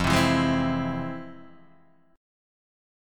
F Minor Major 7th